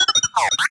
beeps3.ogg